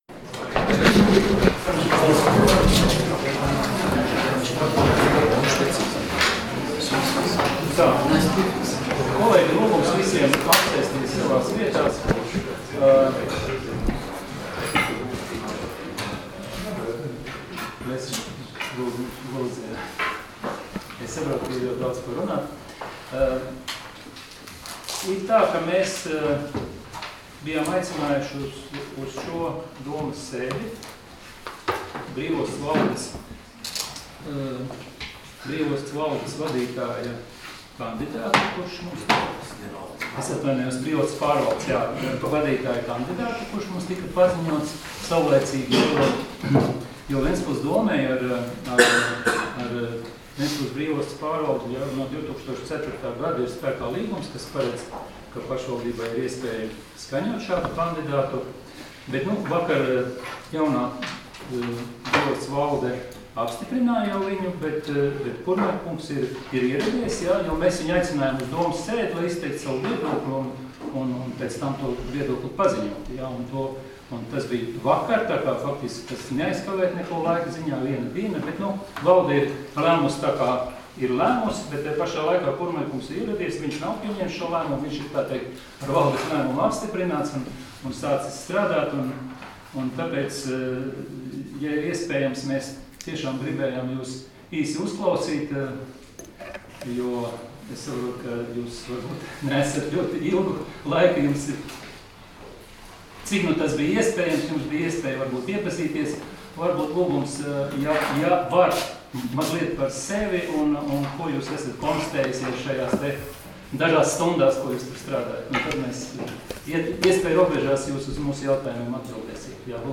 Domes sēdes 18.12.2019. audioieraksts